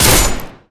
sven_attack3.mp3